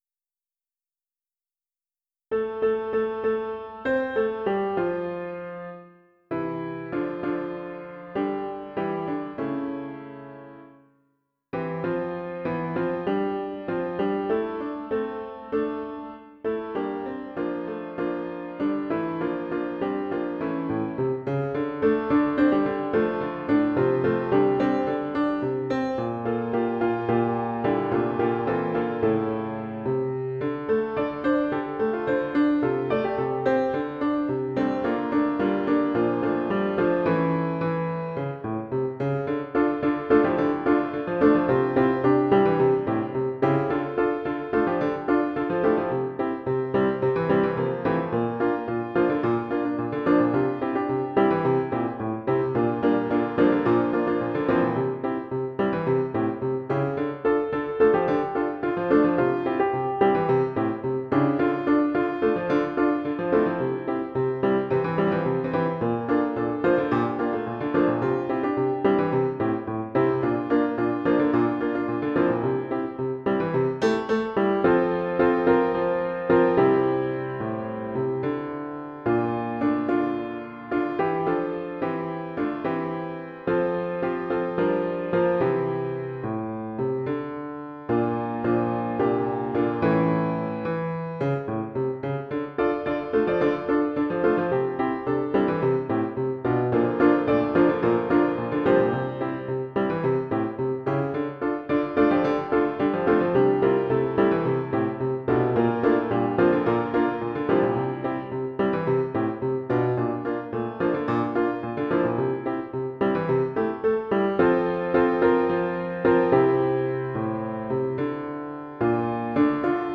Title There's a Lot to Know (SATB) Opus # 310 Year 2006 Duration 00:03:32 Self-Rating 4 Description A meta-song. See the notes at the end for more. mp3 download wav download Files: mp3 wav Tags: Piano, Choral Plays: 1414 Likes: 0